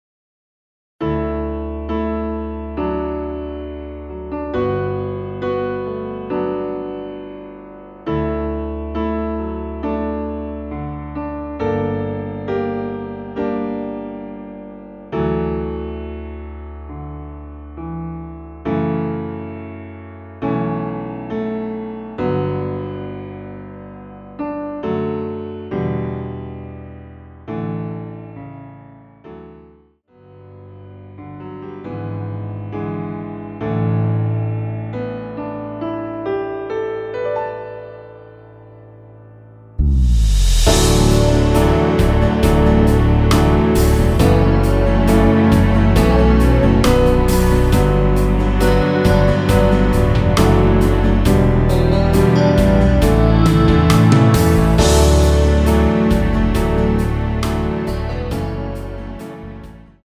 라이브용 버전2절 없이 진행 됩니다.(원키 라이브 버전의 동영상및 아래 가사 참조)
◈ 곡명 옆 (-1)은 반음 내림, (+1)은 반음 올림 입니다.
앞부분30초, 뒷부분30초씩 편집해서 올려 드리고 있습니다.